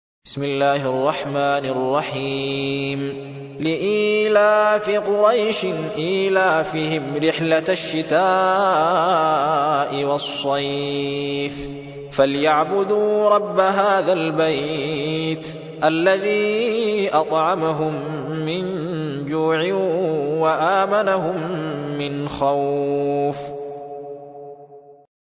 106. سورة قريش / القارئ